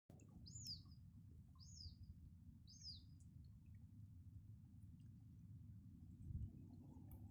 Birds -> Birds of prey ->
Common Buzzard, Buteo buteo
Count1 - 2